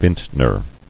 [vint·ner]